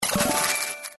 Purchase.mp3